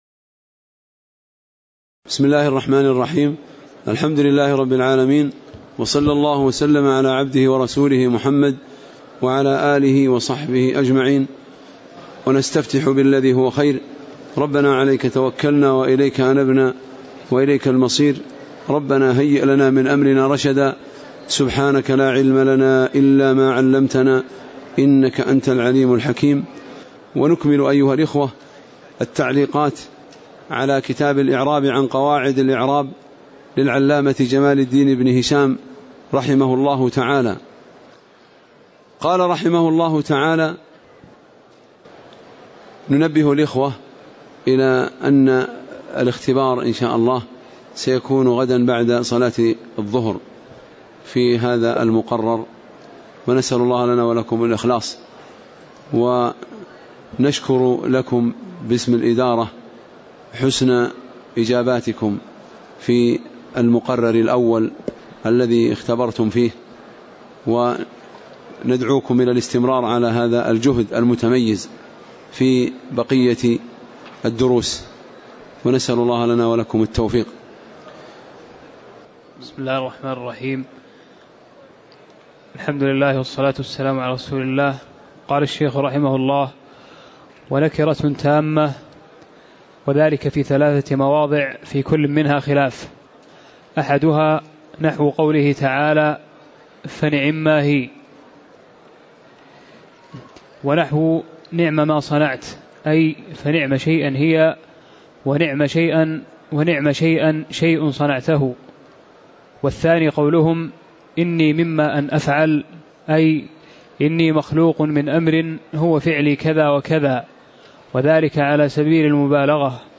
تاريخ النشر ١٠ شوال ١٤٣٨ هـ المكان: المسجد النبوي الشيخ